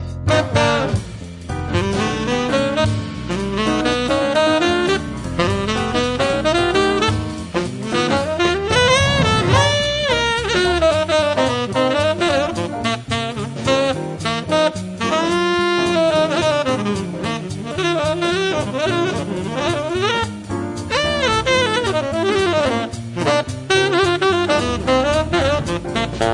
The Best In British Jazz
Recorded Premises Studios, London December 7-9th 1993